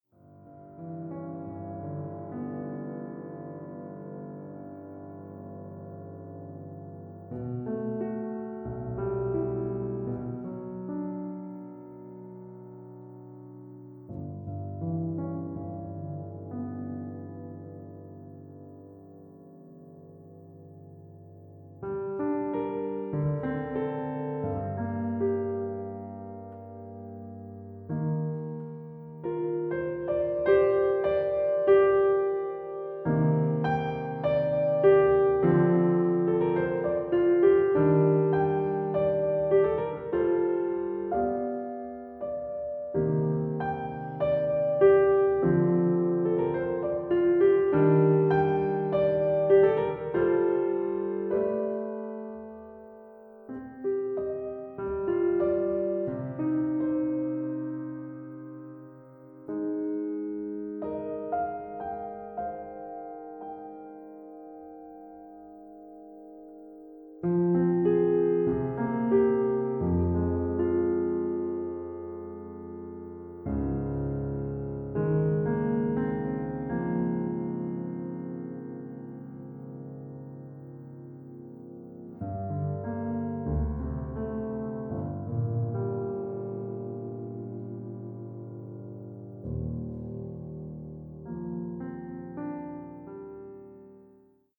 has overt connections to jazz, pop and minimalism
Australian, Classical